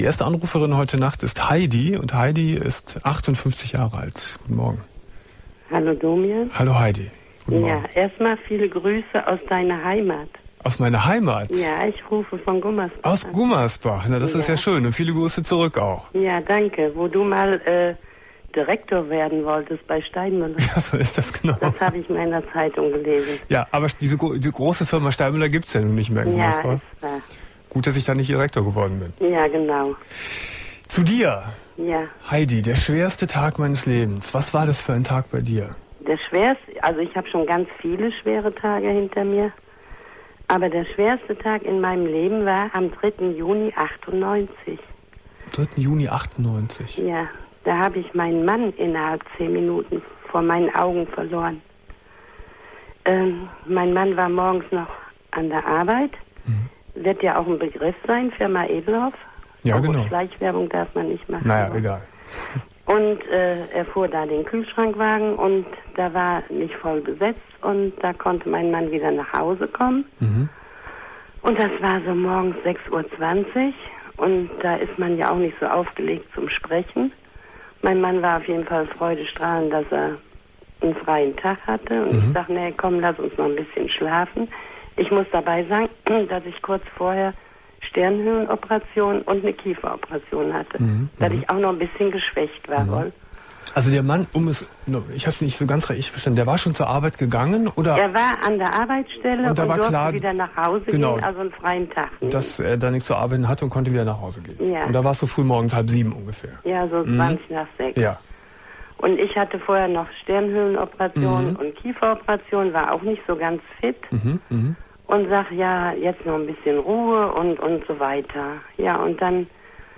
13.06.2001 Domian Thema: Der schwerste Tag meines Lebens ~ Domian Talkradio Archiv Podcast